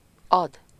Ääntäminen
IPA: /ˈɣeː.və(n)/